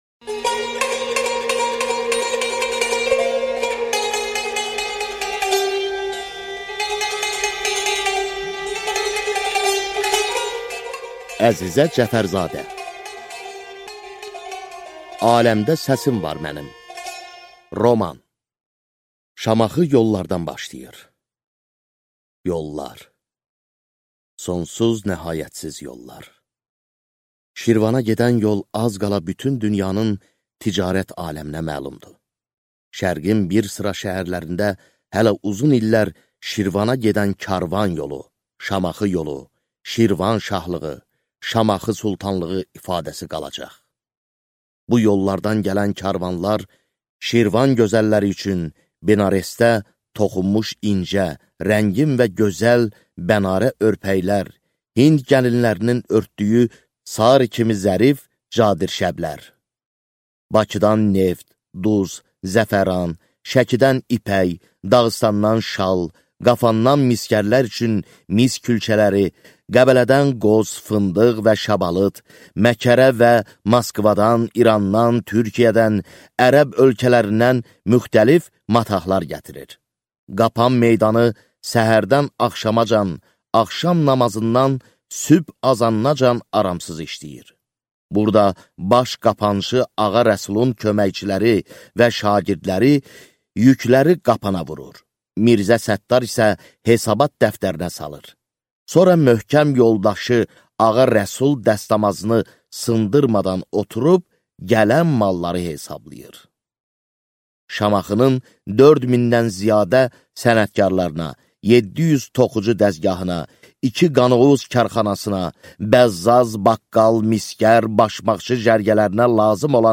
Аудиокнига Aləmdə səsim var mənim | Библиотека аудиокниг